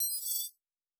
Additional Weapon Sounds 1_2.wav